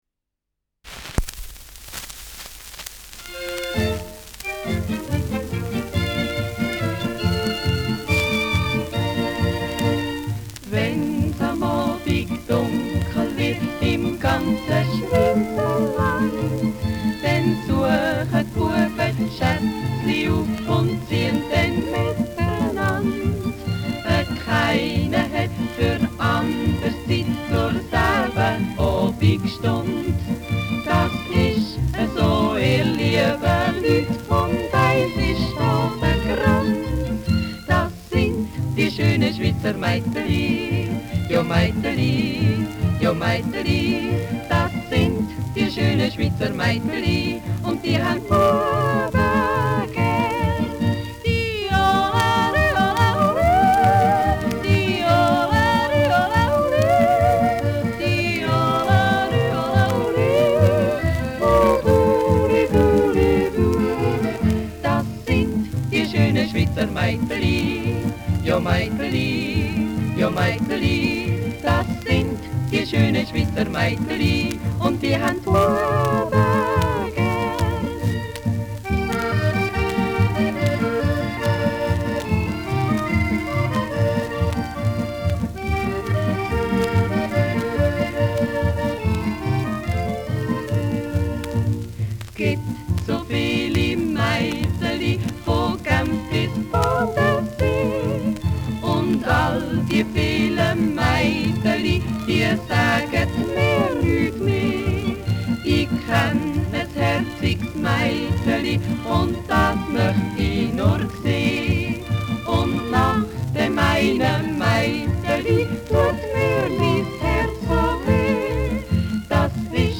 Schellackplatte
Jodlergruppe* FVS-00014